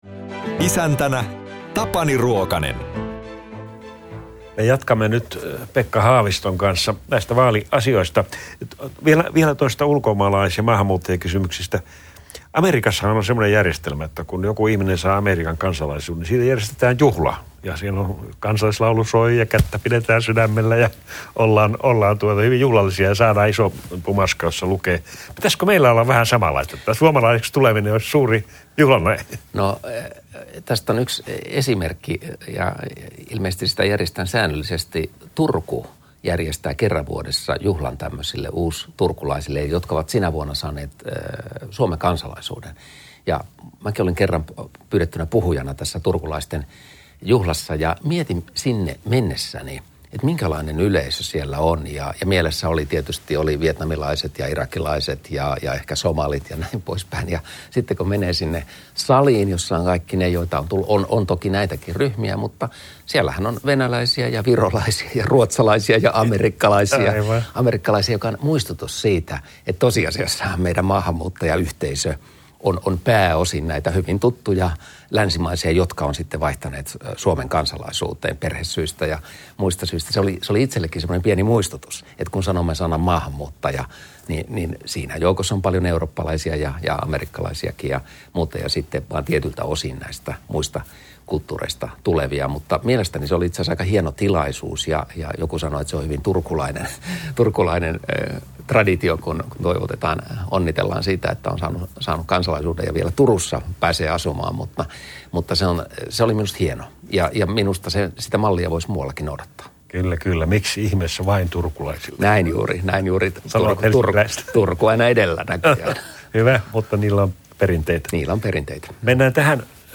Uusinnat puoluejohtajien haastatteluista kuullaan tiistai-iltaisin kello 19.